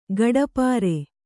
♪ gaḍapāre